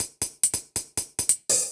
Index of /musicradar/ultimate-hihat-samples/140bpm
UHH_ElectroHatD_140-02.wav